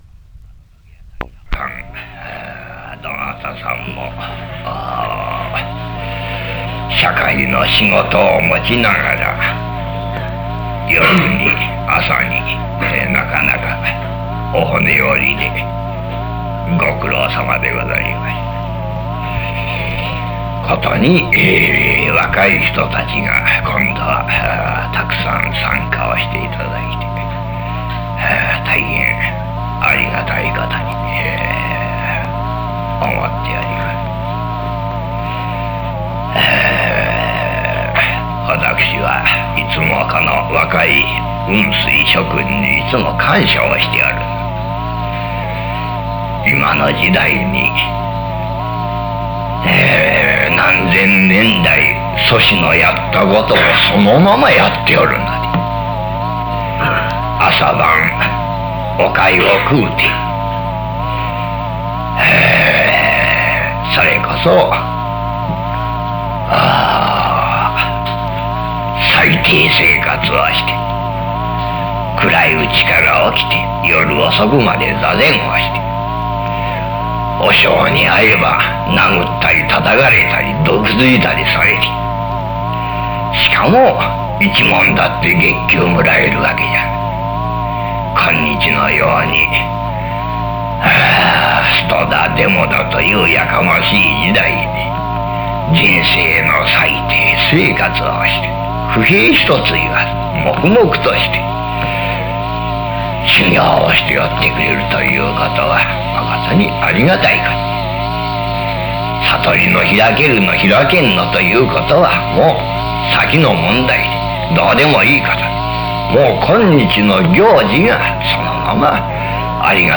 ※ノイズがきつい箇所がございます。お聞きの際はご注意ください。